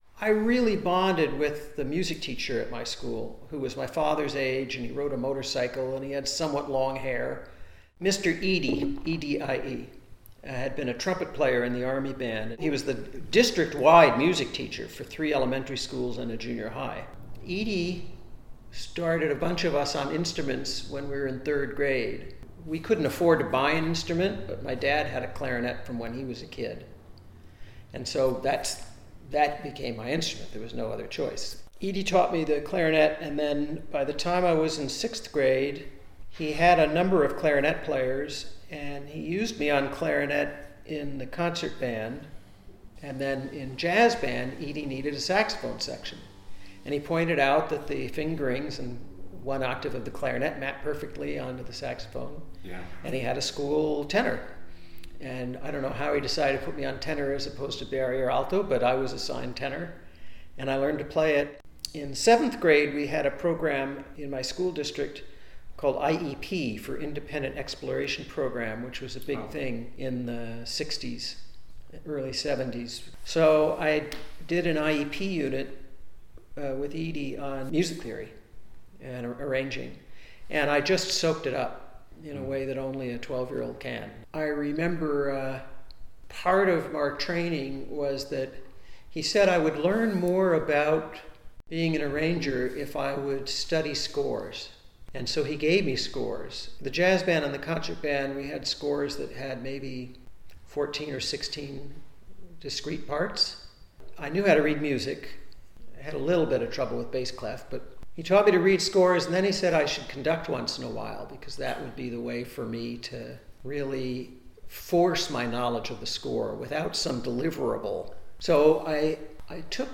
Dr. Levitin describes his start in music in the following excerpt: